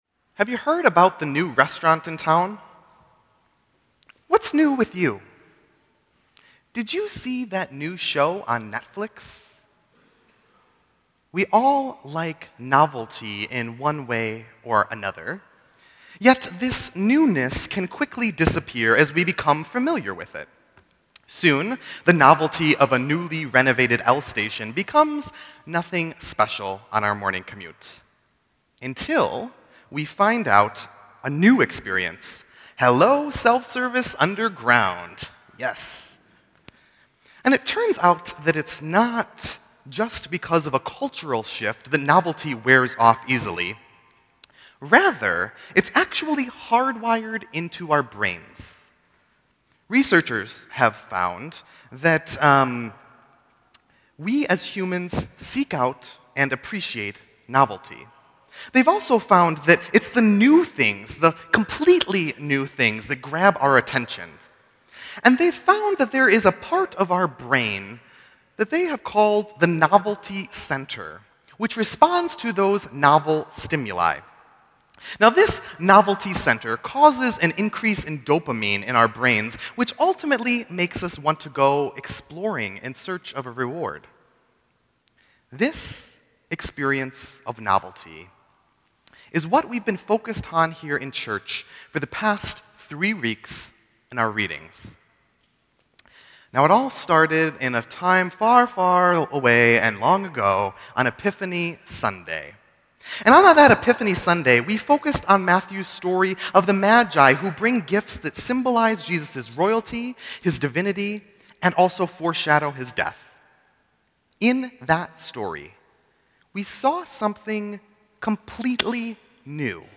Sermon_1_17_16.mp3